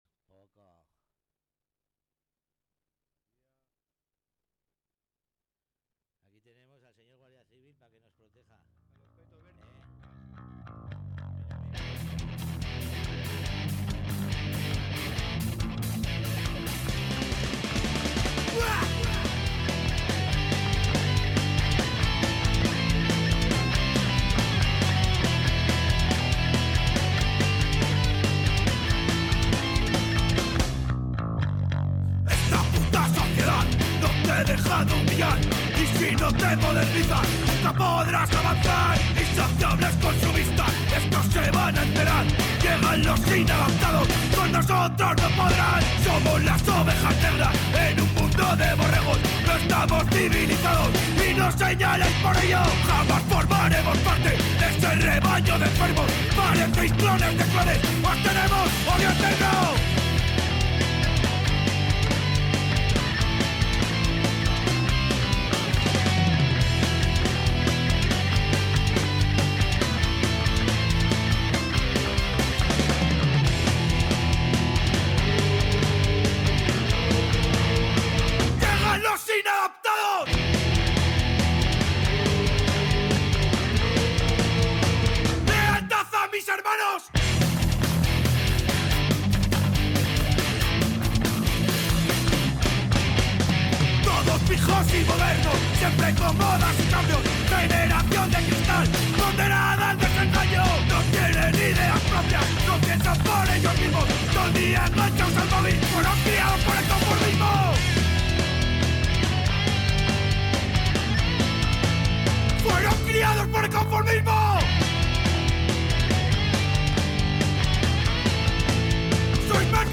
En esta ocasión tuvimos el placer de entrevistar a la banda